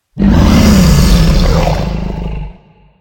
dragon_growl3.ogg